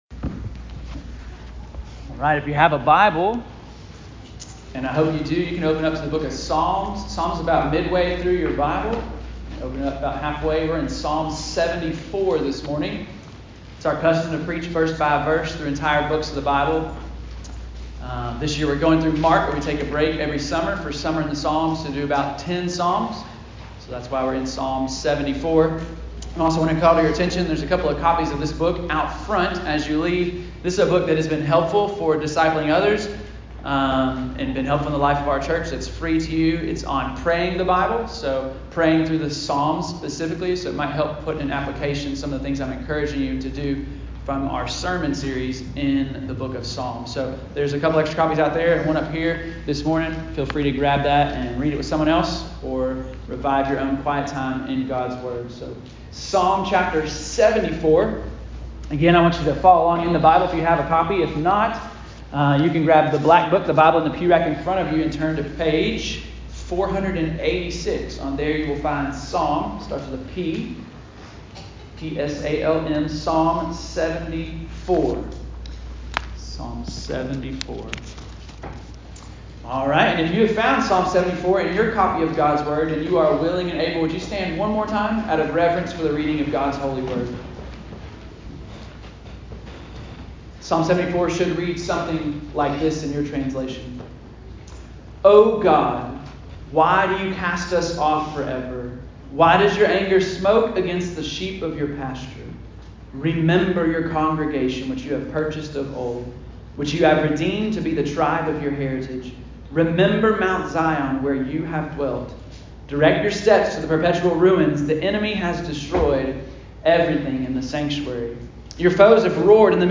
Bethany Baptist Church Listen to Sermons